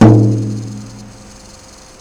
Instrument samples/percussion
14in tom